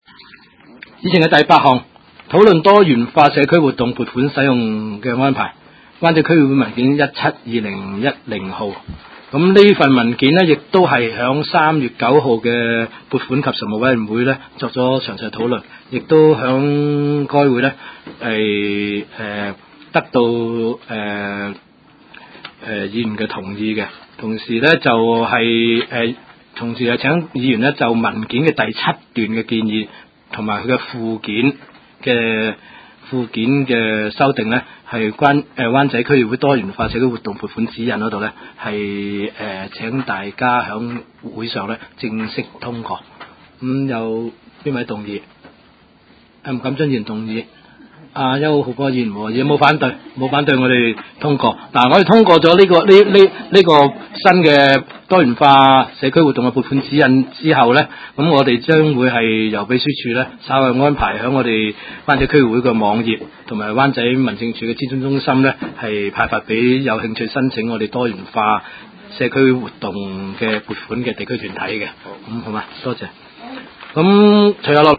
灣仔區議會第十五次會議
灣仔民政事務處區議會會議室